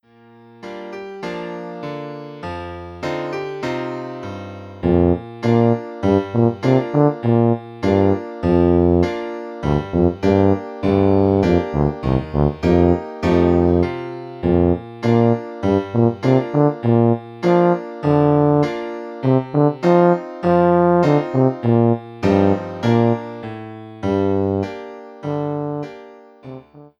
is a miniature for tuba and piano